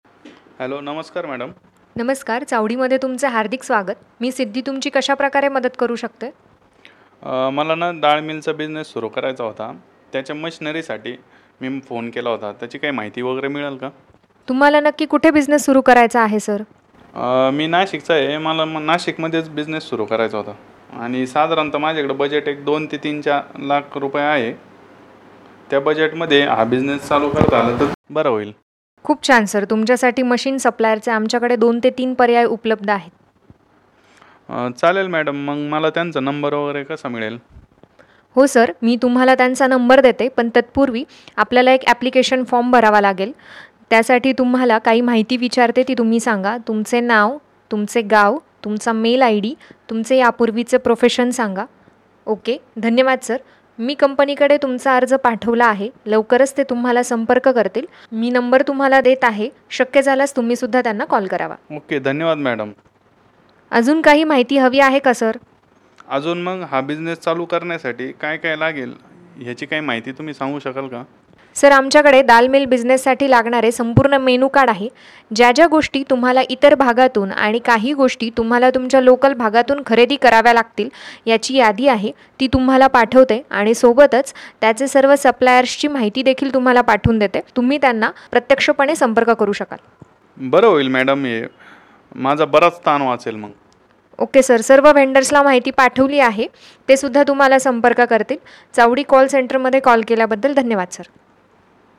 One Demo Call How Call Center Works..